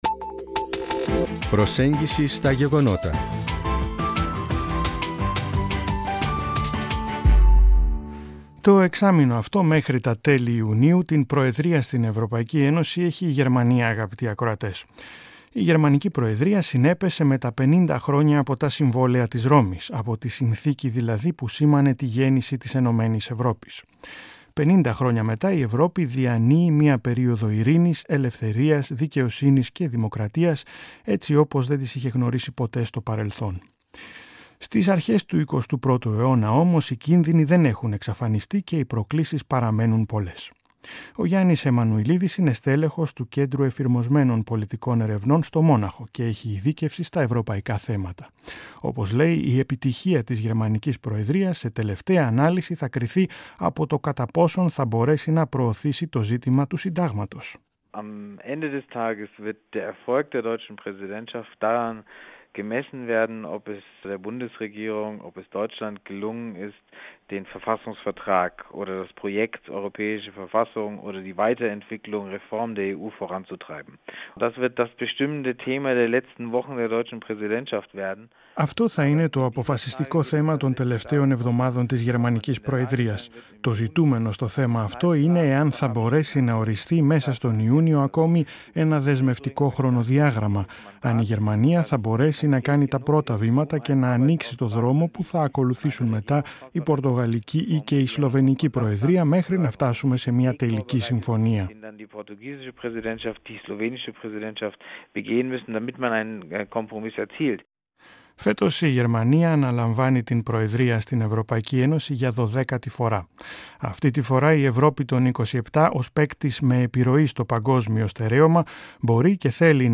Interview
Das Interview lief im griechischen Programm des Hessischen Rundfunks am 17.Mai 2007.